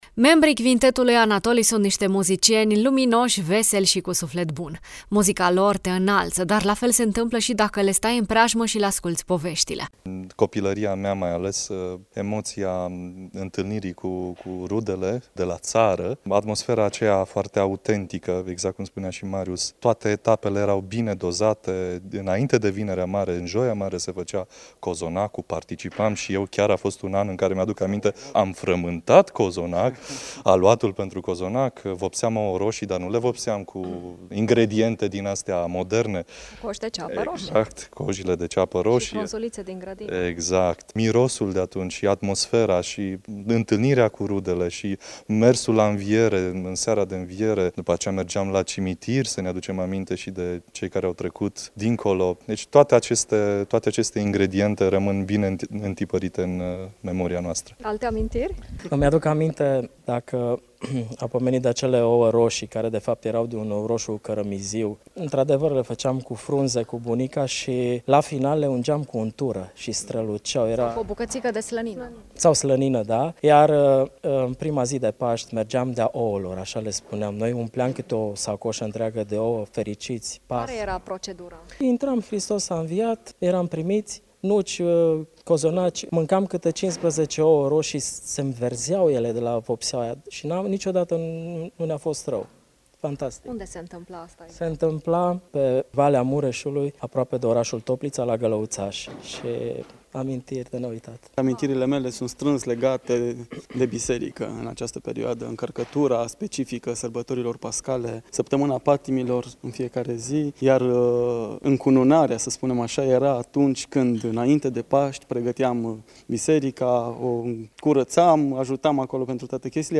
Paștele e sărbătoarea care ne aduce acasă – în suflet, în amintiri, în cântec. În Bucuria de a fi de azi, membrii Cvintetului Anatoly ne deschid o fereastră spre copilăria lor, spre tradițiile și emoțiile care le-au luminat sărbătorile pascale.